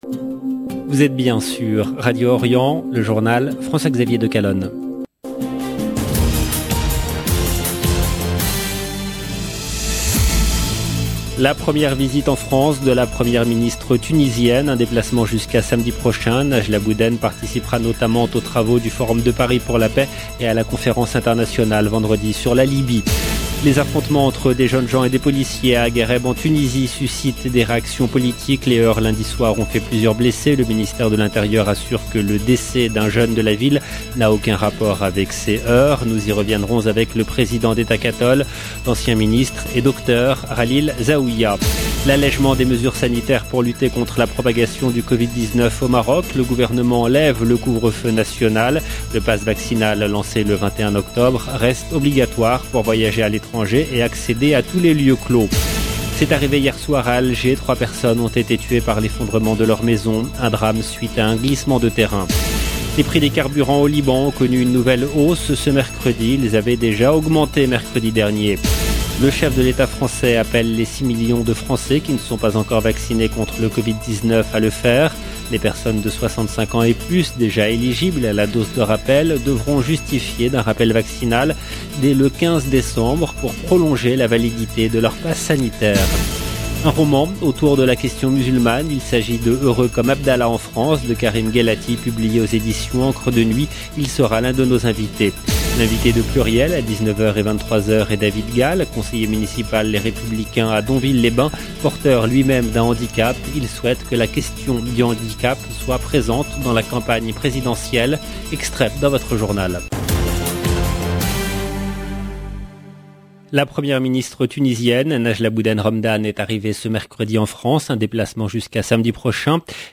LE JOURNAL EN LANGUE FRANCAISE DU SOIR DU 10/11/21